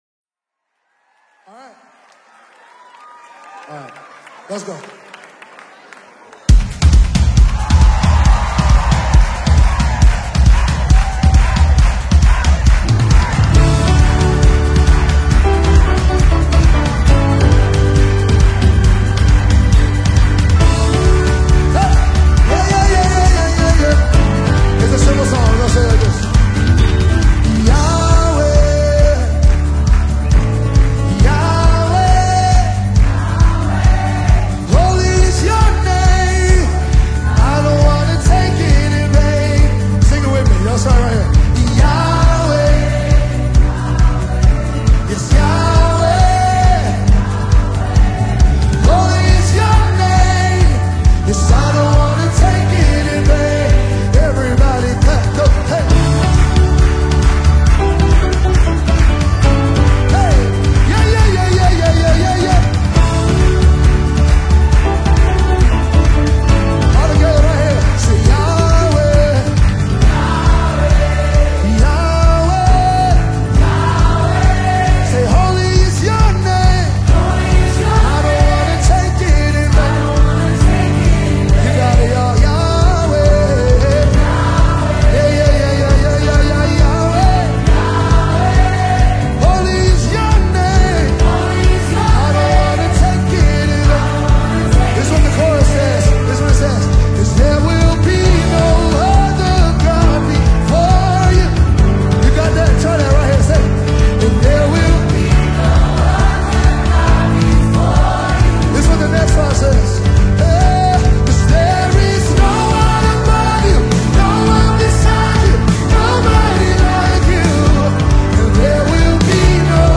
an acclaimed gospel music group